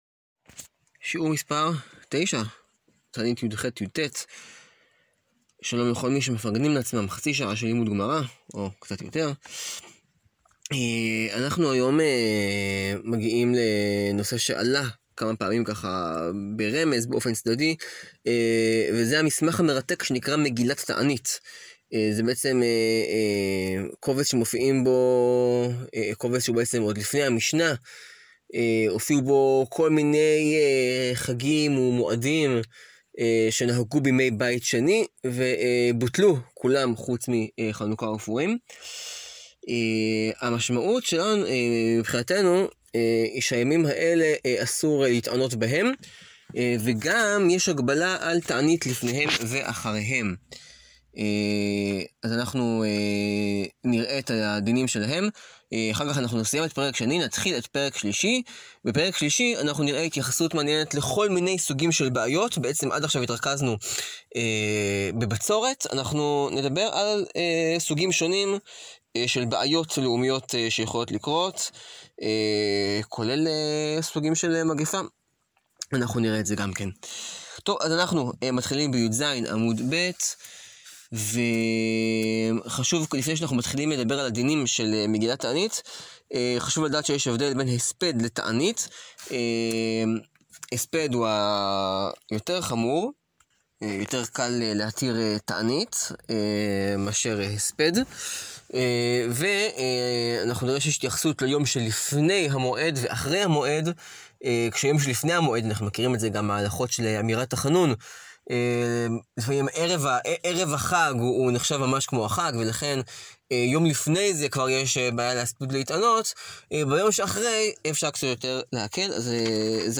סיום מסכת תענית – שיעור 9